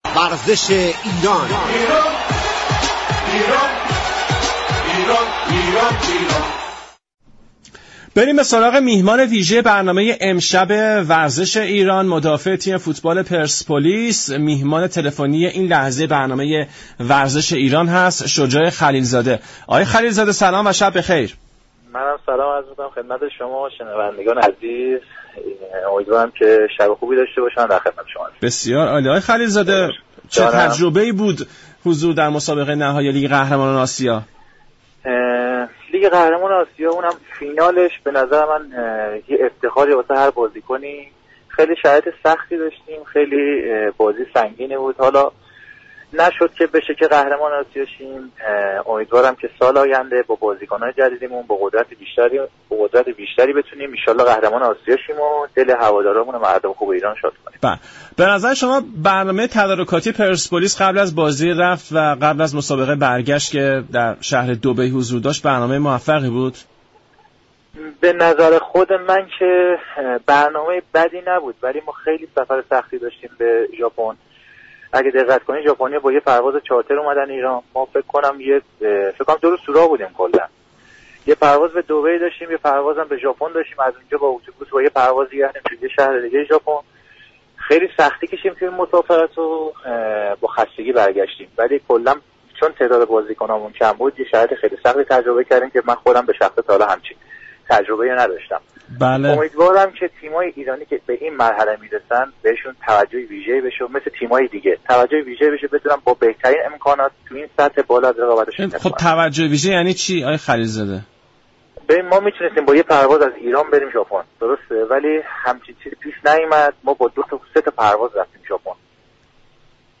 شجاع خلیل زاده مدافع تیم پرسپولیس در گفت و گو با رادیو ایران گفت